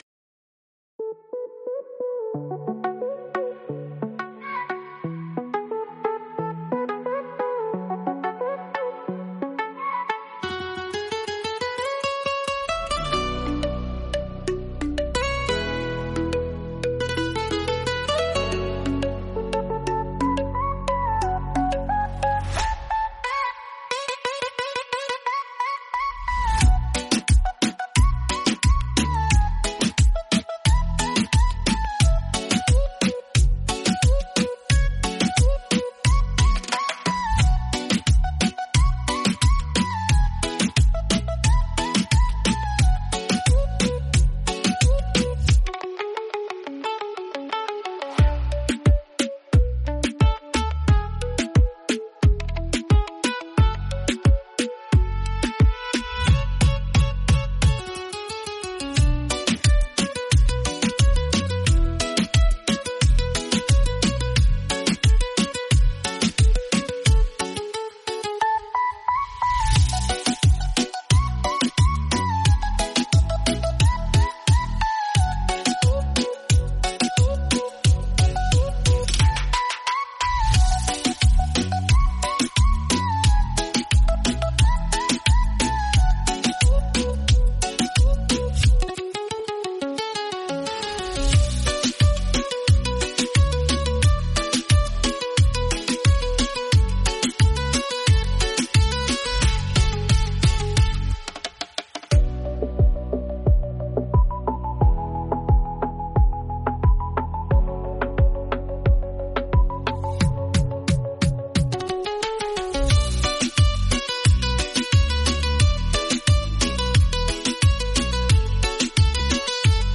Beat Reggaeton Instrumental
Acapella e Cori Reggaeton Inclusi
• Mix e mastering di qualità studio
Bm